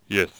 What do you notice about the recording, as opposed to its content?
khanat-sounds-sources - Source files to create all the .wav used in the Khanat game